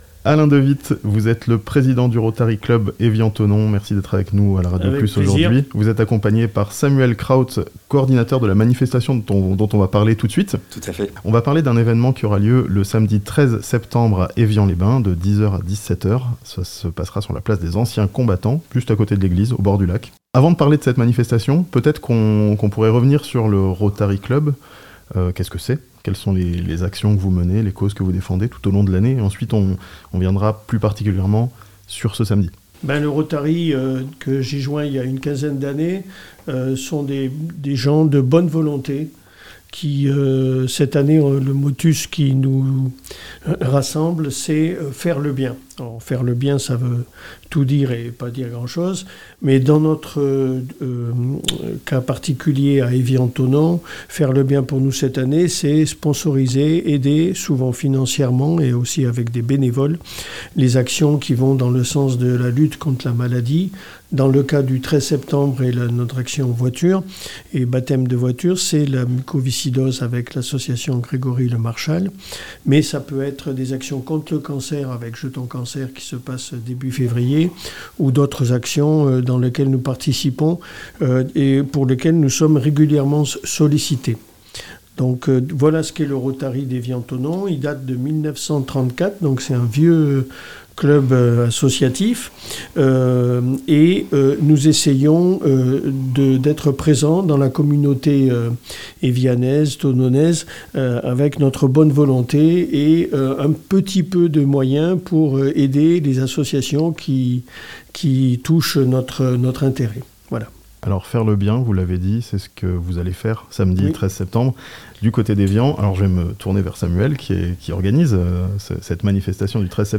Des baptêmes en voitures de prestige, ce samedi à Evian, pour la bonne cause (interview)